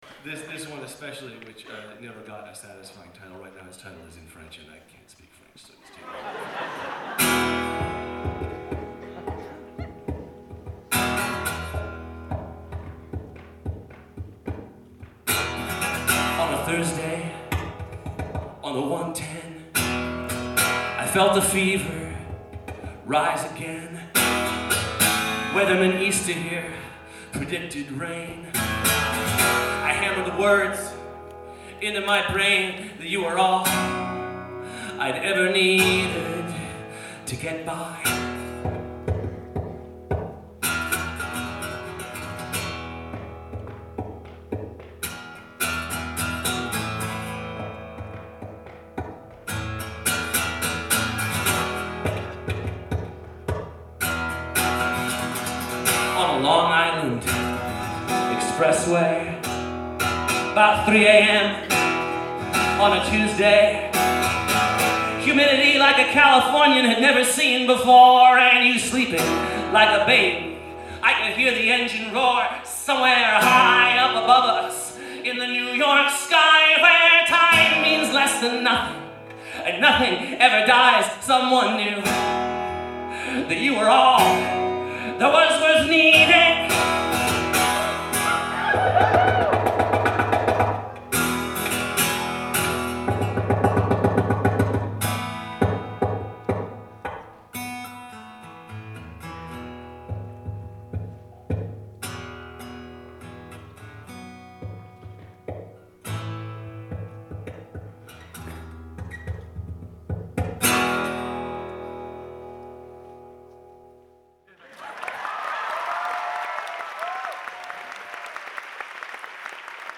Live at the Somerville Theatre